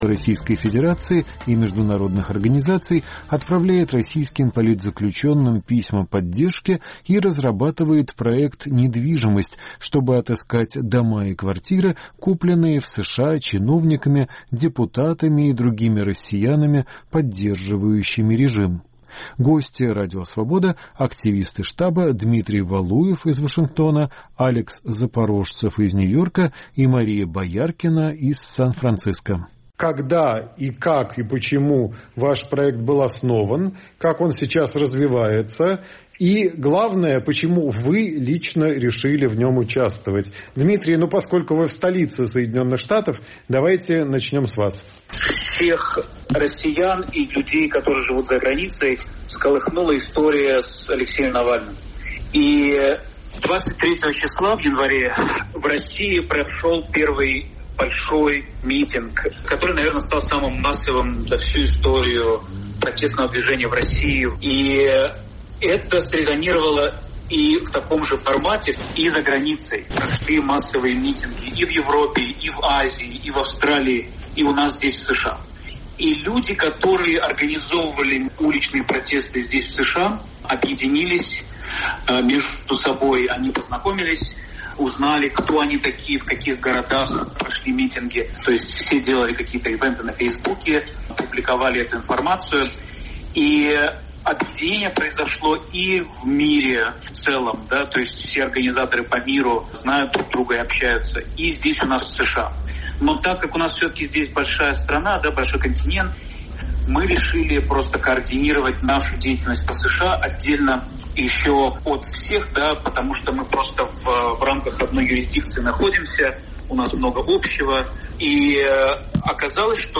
Звучат голоса участников объединения российских эмигрантов, выступающих против путинского режима